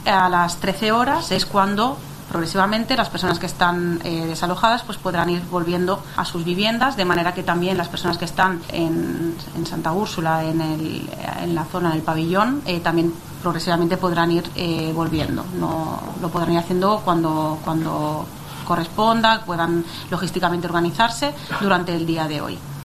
Montse Román, jefa de Protección Civil de Canarias, anuncia el regreso de los evacuados